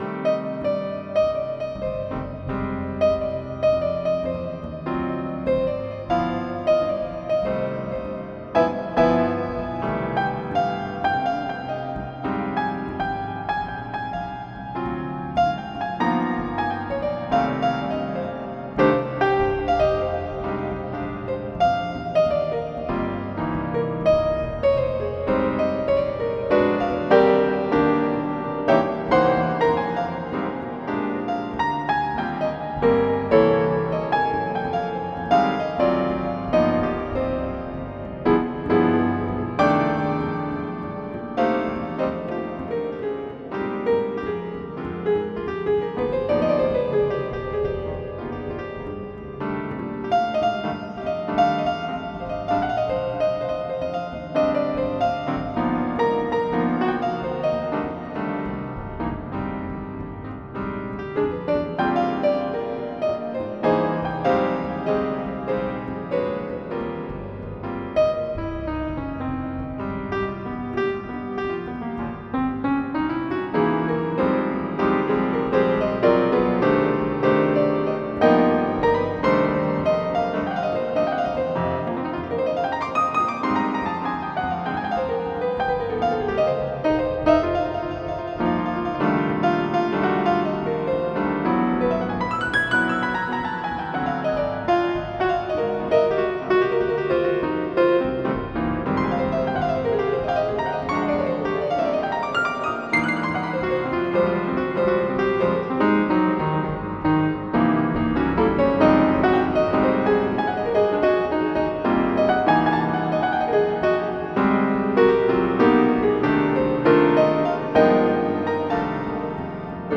Jazz / NewBop.mid
MIDI Music File